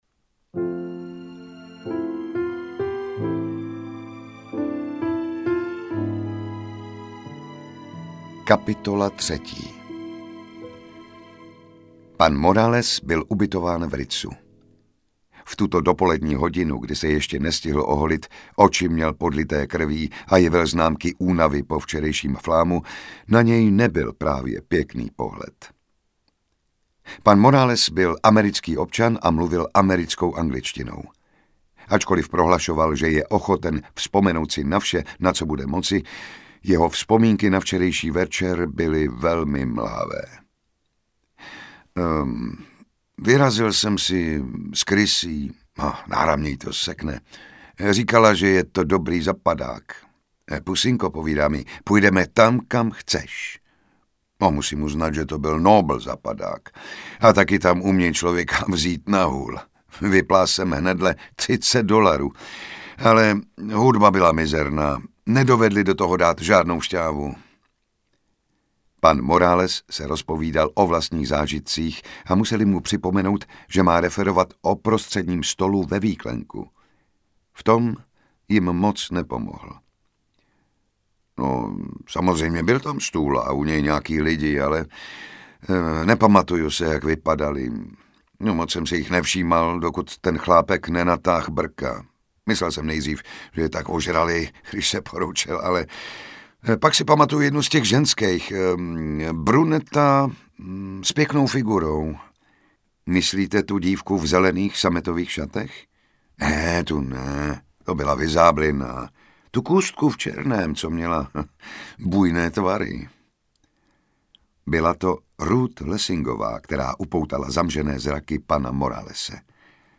Cyankáli v šampaňském audiokniha
Ukázka z knihy
• InterpretJan Šťastný
cyankali-v-sampanskem-audiokniha